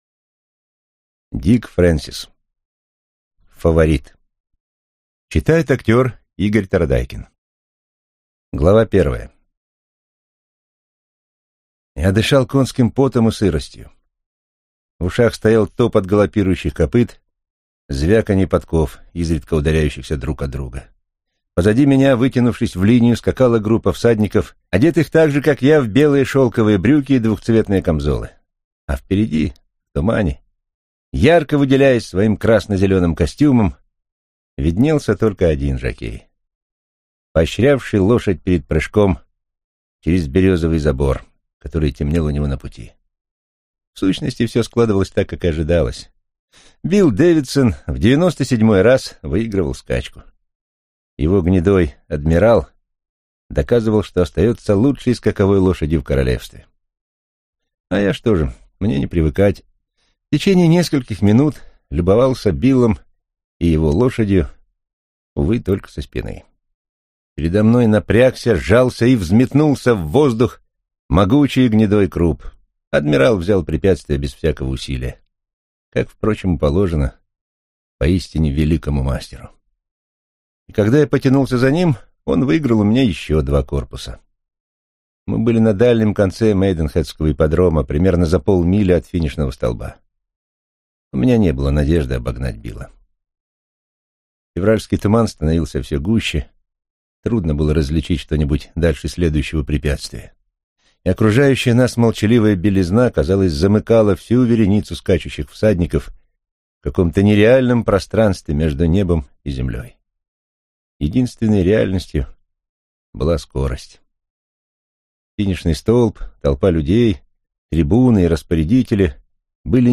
Аудиокнига Фаворит | Библиотека аудиокниг